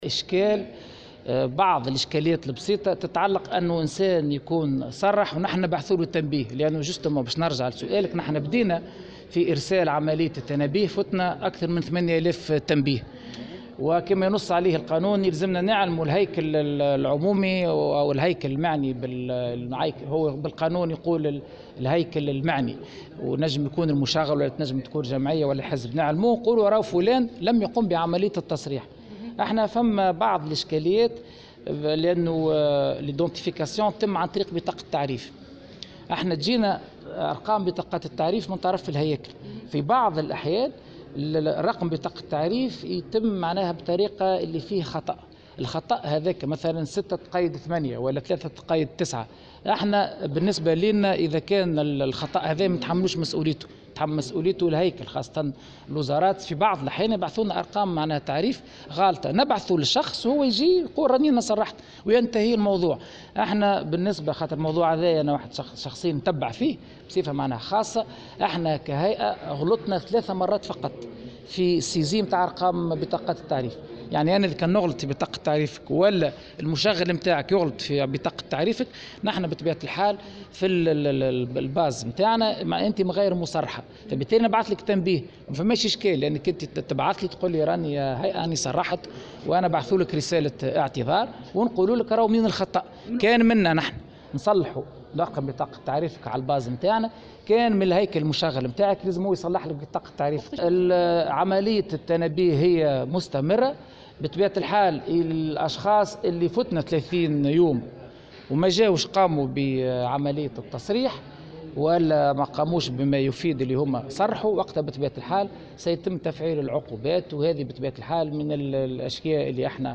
وأضاف الطبيب في تصريح اليوم لمراسلة "الجوهرة أف أم" على هامش ورشة عمل نظمتها الهيئة بعنوان " التصريح بالمكاسب والمصالح وتكوين قاعدة البيانات" أنه في صورة تجاوز المدة القانونية سيتم تفعيل العقوبات إما المتعلقة بالخصم من الأجر أو المنحة أو تسليط خطايا مالية والسجن، وفق قوله.